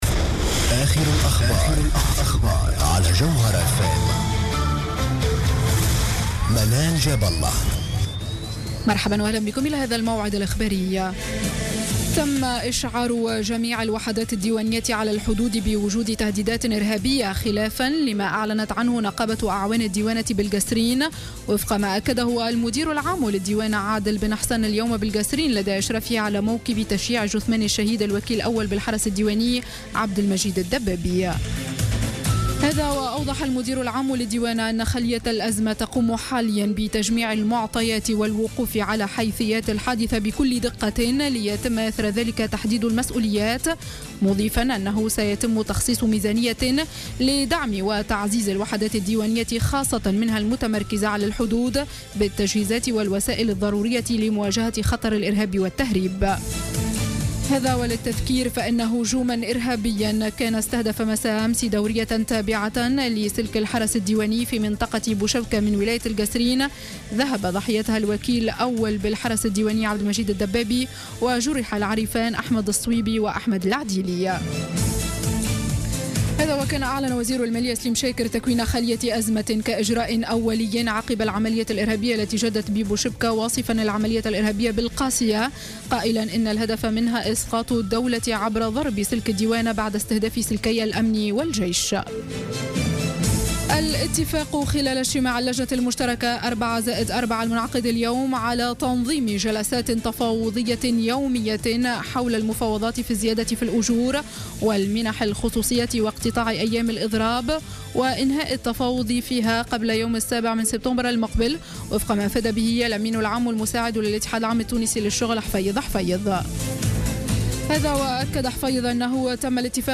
نشرة أخبار السابعة مساء ليوم الاثنين 24 أوت 2015